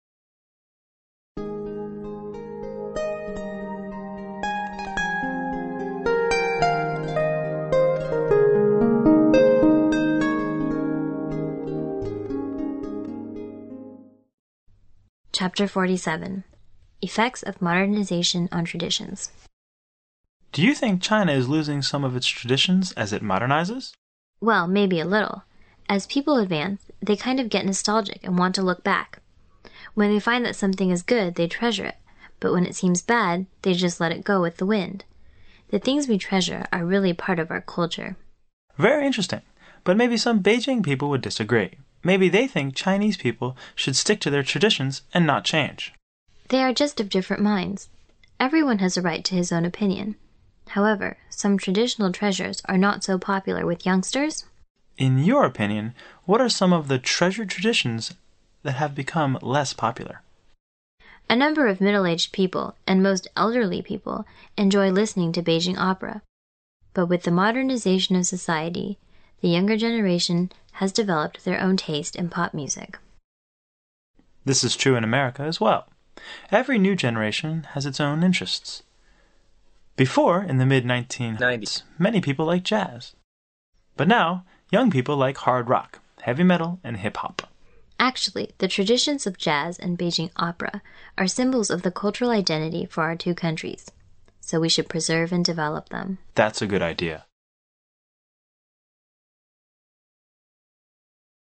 原汁原味的语言素材，习得口语的最佳语境。